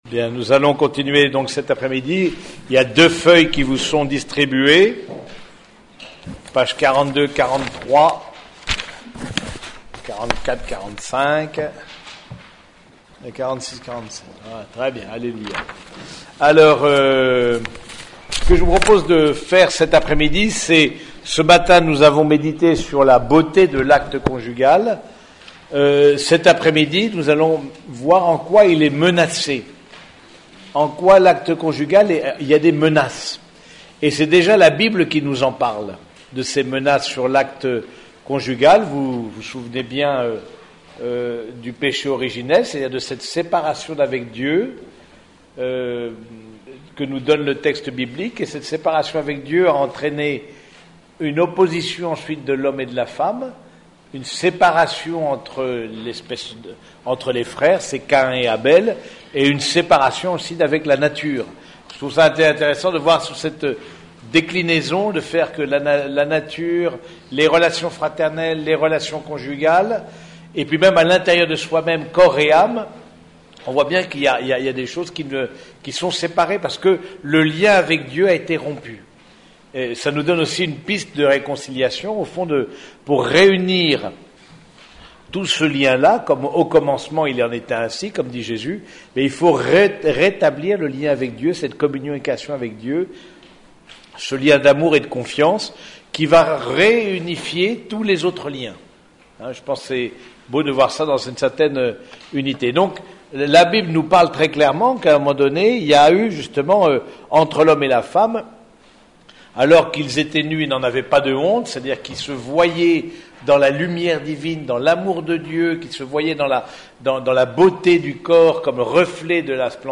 Écouter les enseignements :